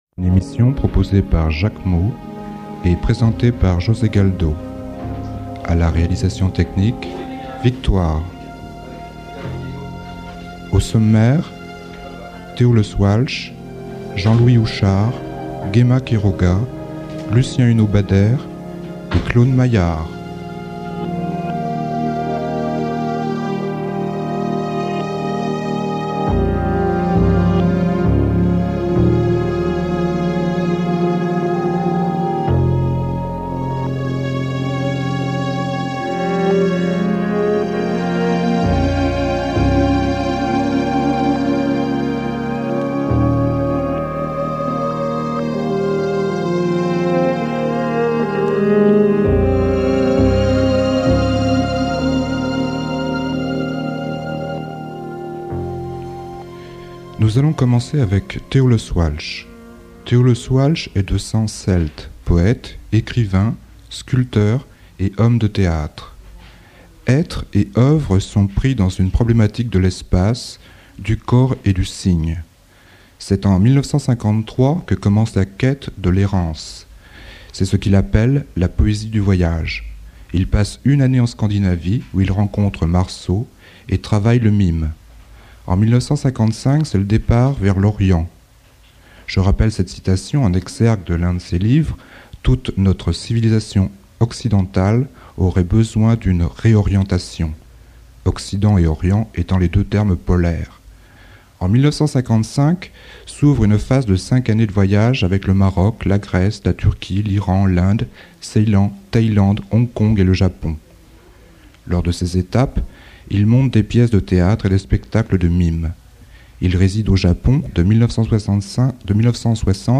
LECTURE SUIVIE PAR UNE MUSIQUE DU JAPON IMP�RIAL POUR LE RITUEL DU TH�